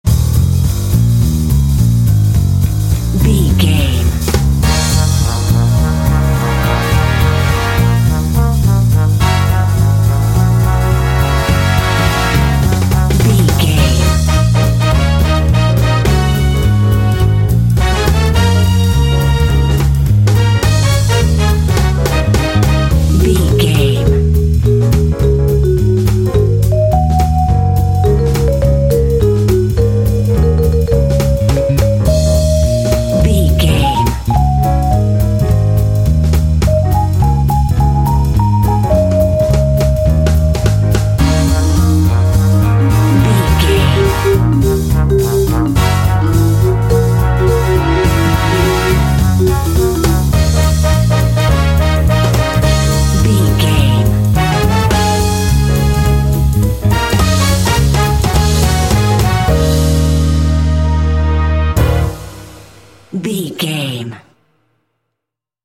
Aeolian/Minor
E♭
epic
driving
energetic
groovy
lively
bass guitar
piano
drums
brass
jazz
big band